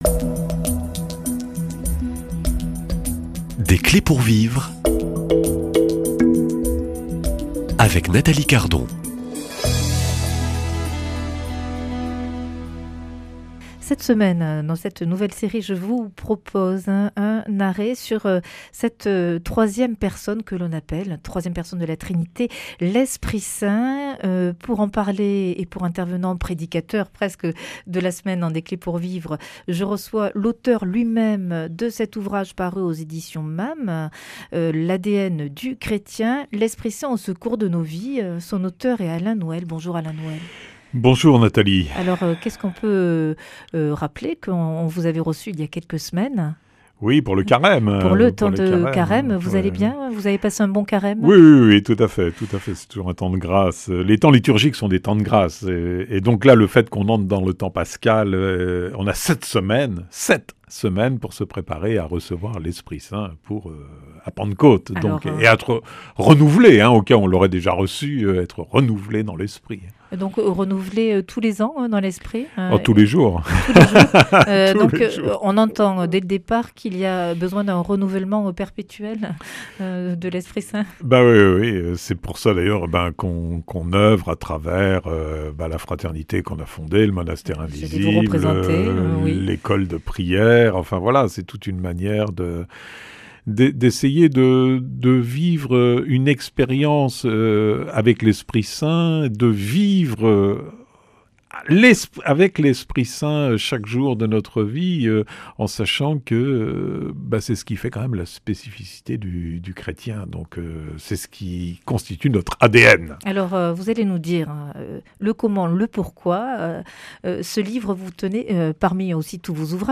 Nouvelle série de 5 entretiens dans Des clés pour vivre cette semaine